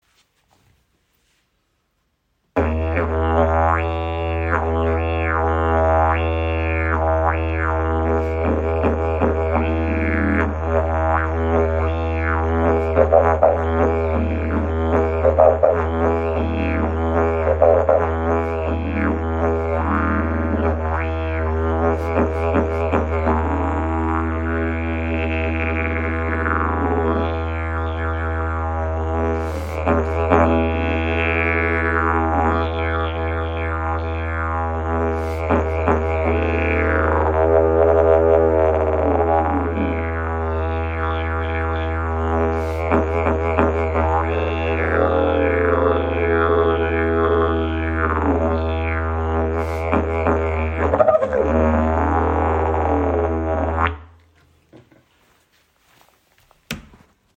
Klangbeispiel
Dieses edle Wurzeldidgeridoo ist aus Eukalyptusholz und wurde von mir nochmals überarbeitet, das Mundstück verfeinert und die Bellöffnung sauber ausgenommen. Es hat einen optimal Luftgegendruck, erlaubt saubere Zungenlaute und gibt den Stimmvariationen, mit seiner grossen Wurzelöffnung, einen sehr schönen Klangraum.
Weiterlesen Klangbeispiel Didgeridoo in E (Handy)